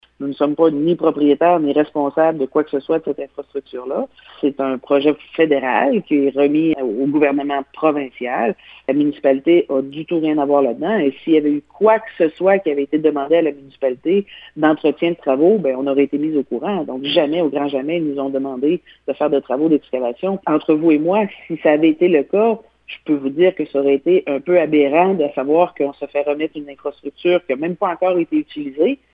La mairesse Cathy Poirier affirme que cette responsabilité n’incombe aucunement à sa municipalité :